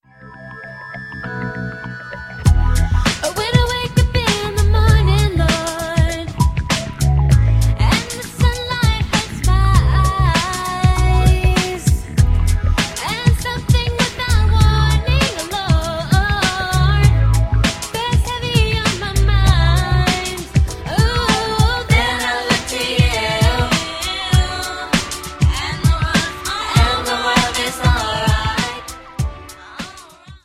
Ist das Gospel, ist das Soul, ist das R'n'B?
• Sachgebiet: Urban